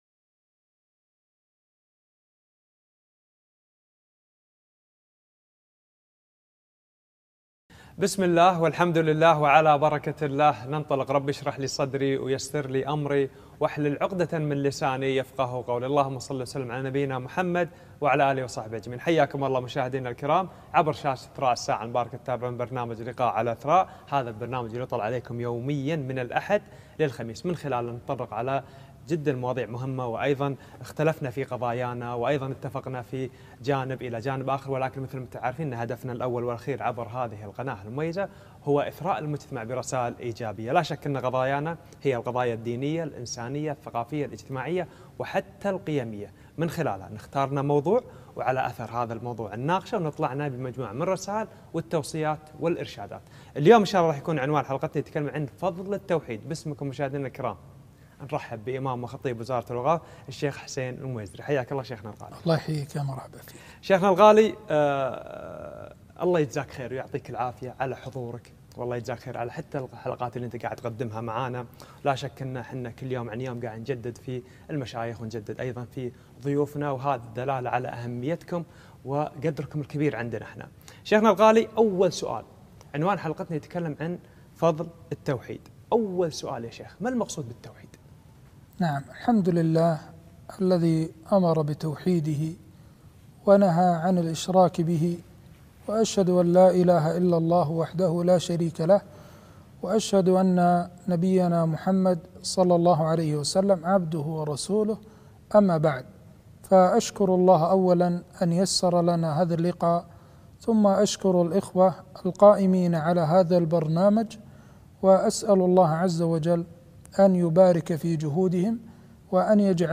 فضل التوحيد ومايكفر من الذنوب - لقاء على قناة إثراء